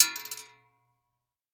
Bullet Shell Sounds
pistol_metal_6.ogg